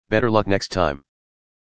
round-lose.mp3